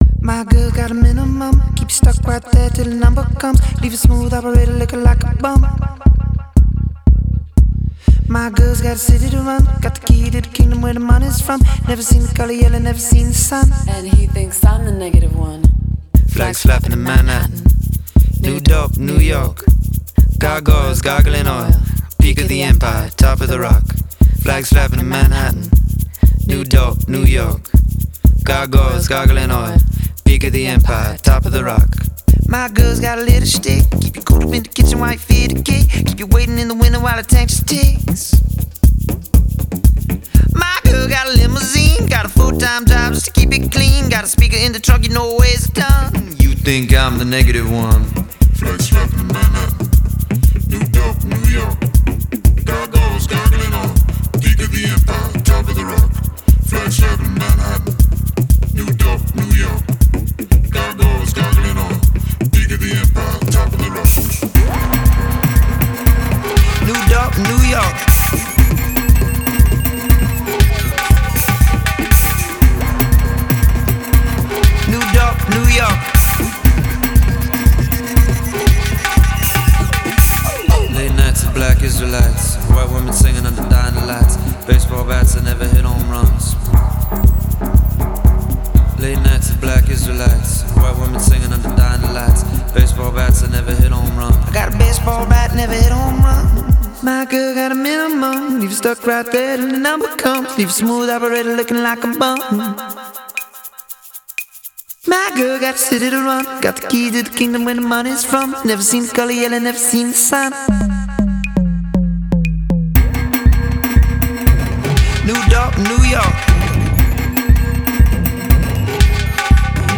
The vocals are flat and electronically treated